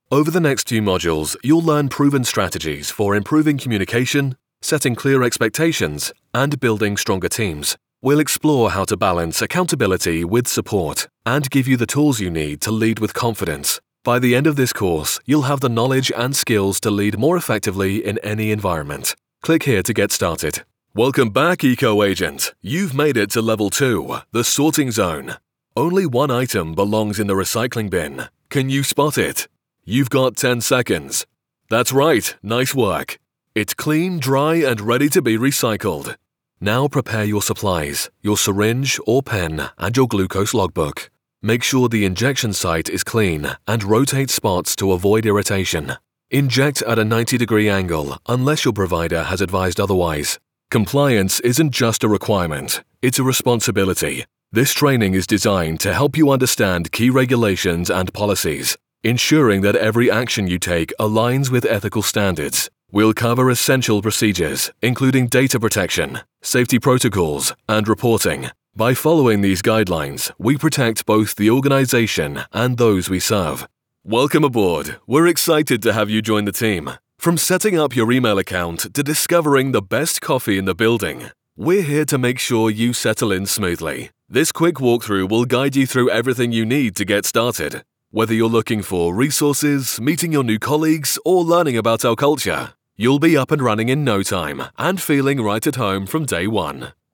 Inglés (Británico)
Profundo, Cool, Versátil, Natural, Llamativo
E-learning